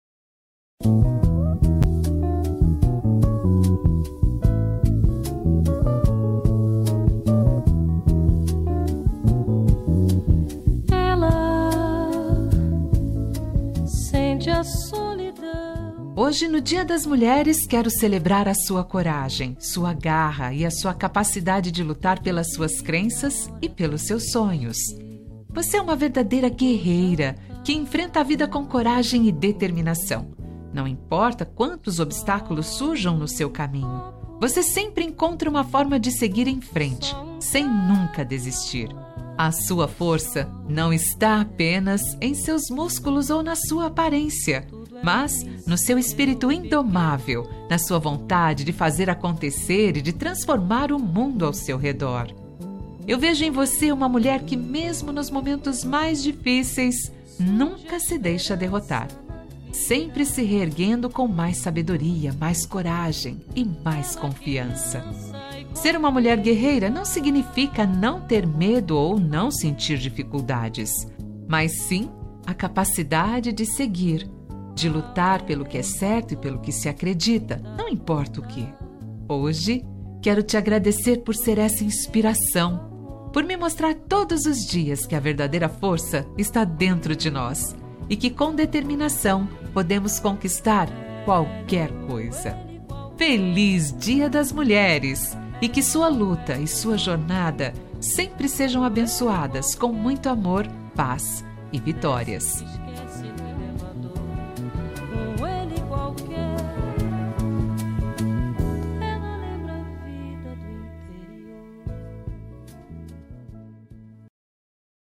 Dia da Mulher – Neutra – Feminina – Cód: 690711